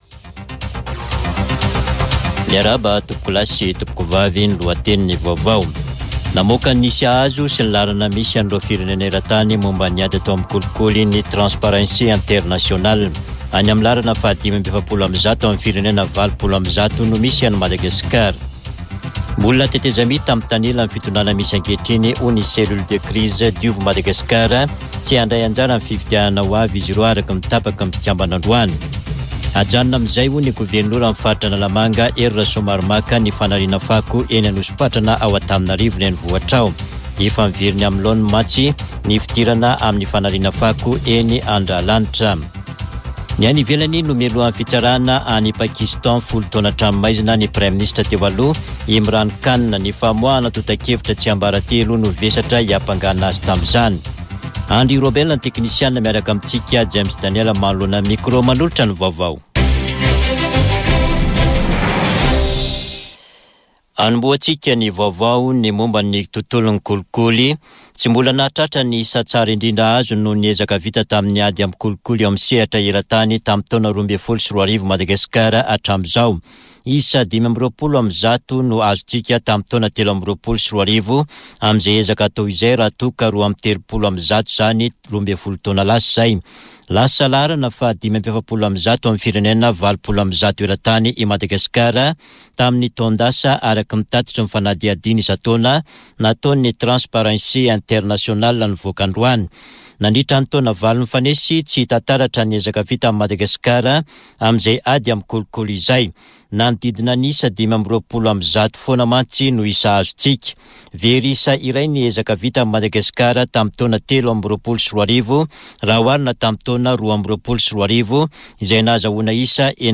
[Vaovao hariva] Talata 30 janoary 2024